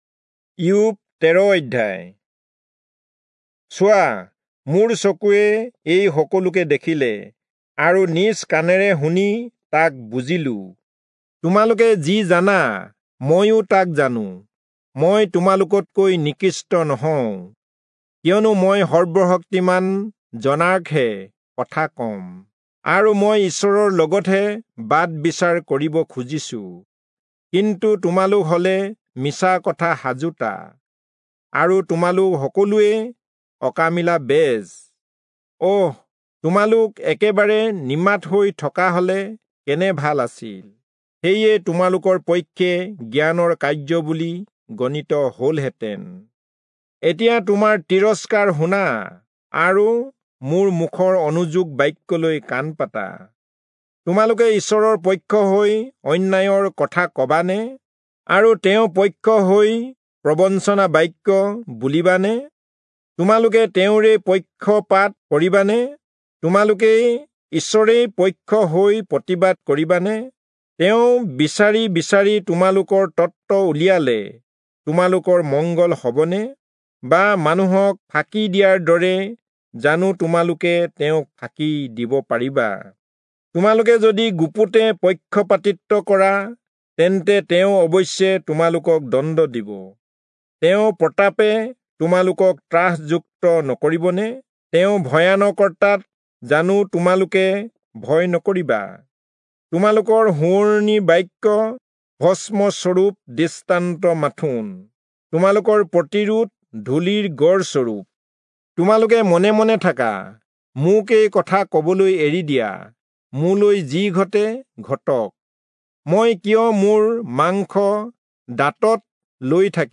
Assamese Audio Bible - Job 14 in Mrv bible version